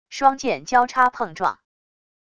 双剑交叉碰撞wav音频